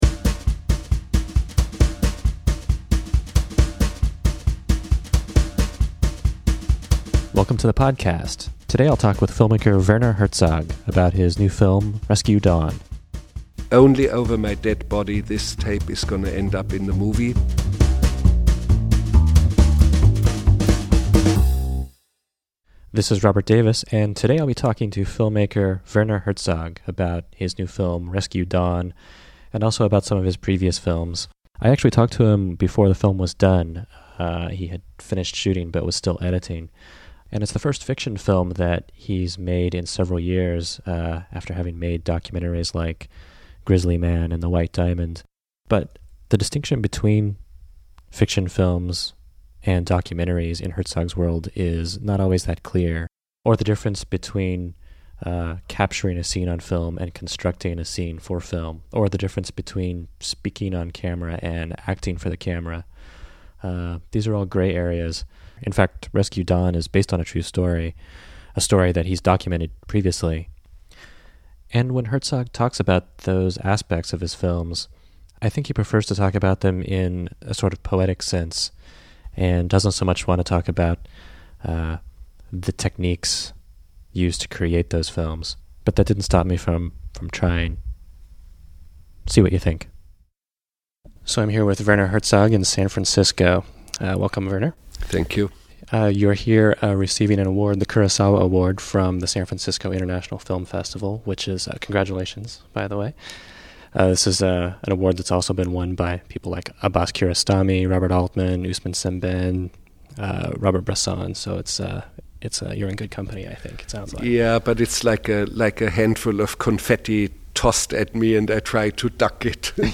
Errata: Interview: Werner Herzog's Rescue Dawn
The third episode of the Errata podcast features a chat with Werner Herzog about Rescue Dawn, Grizzly Man, The White Diamond, and other films.